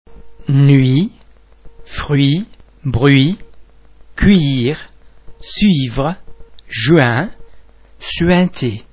Please be mindful of the fact that all the French sounds are produced with greater facial, throat and other phonatory muscle tension than any English sound.
The French [u+vowel ] sound is actually the French [ u ] sound pronounced before an [ i ], [ y ], [e] or [a] but perhaps shorter and more like just a transition sound.